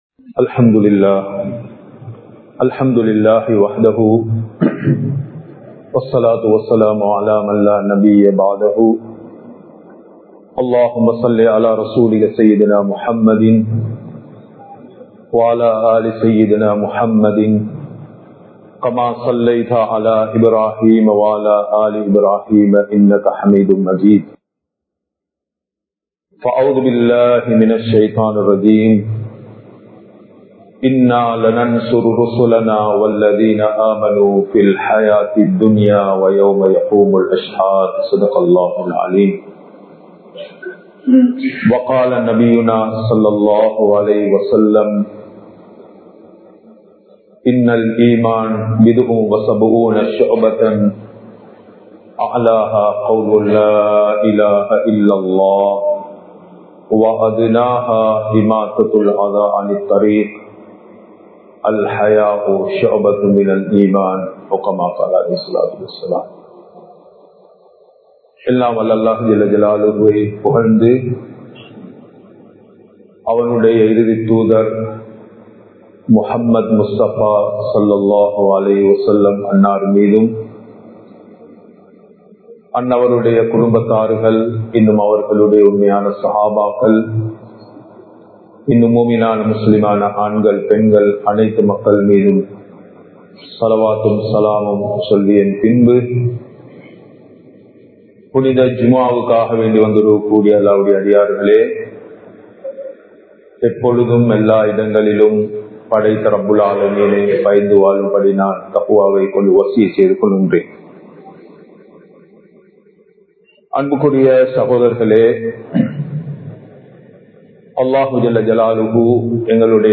04 வகையான குழப்பங்கள் | Audio Bayans | All Ceylon Muslim Youth Community | Addalaichenai